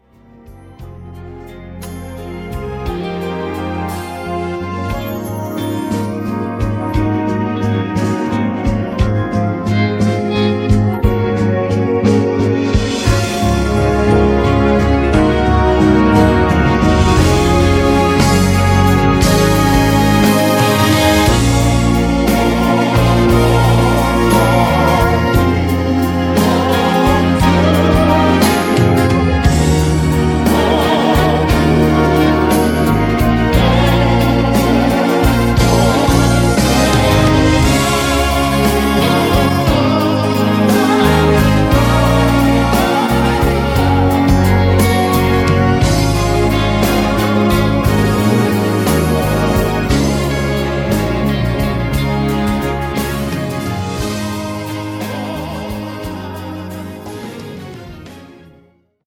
음정 -1키 4:48
장르 가요 구분 Voice MR